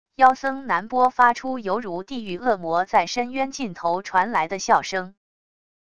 妖僧南波发出犹如地狱恶魔在深渊尽头传来的笑声wav音频